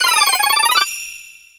Cri de Korillon dans Pokémon X et Y.